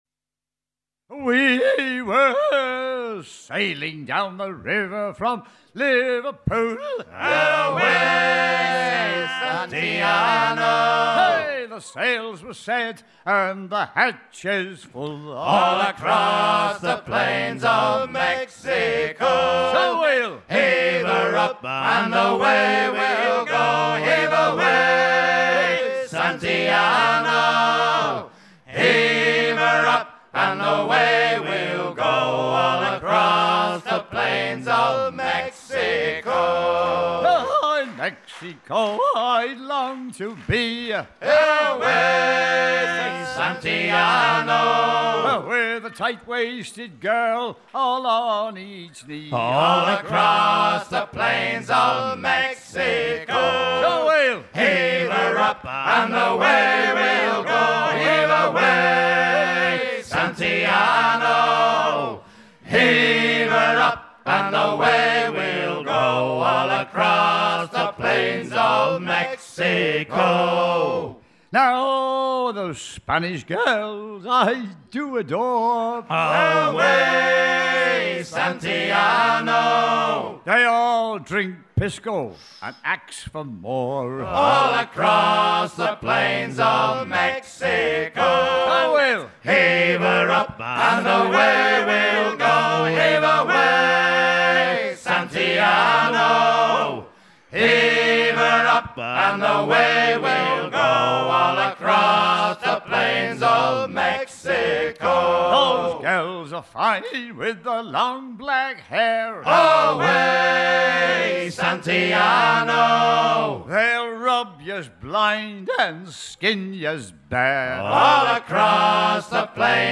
chant des long-courriers
à hisser main sur main
Pièce musicale éditée